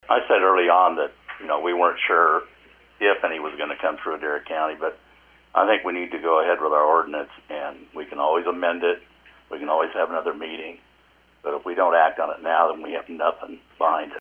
Supervisor John Twombly preferred to move forward with the Ordinance.